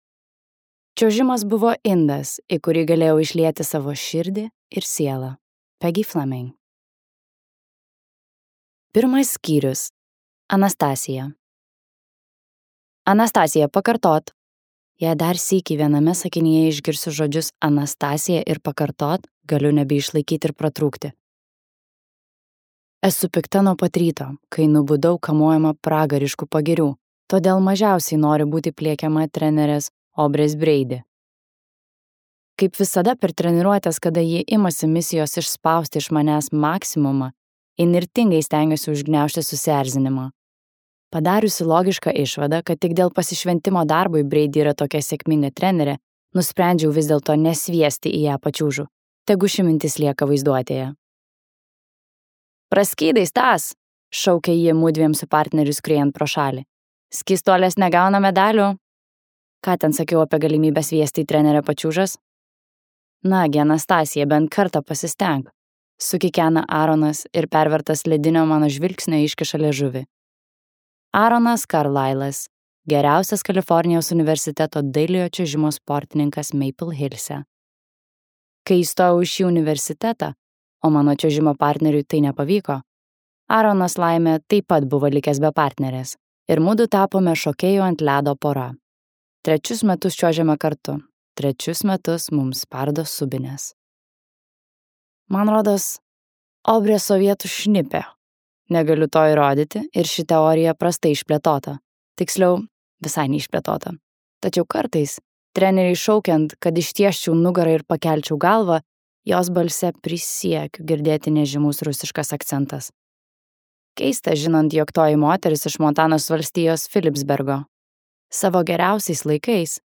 Pralaužtas ledas | Audioknygos | baltos lankos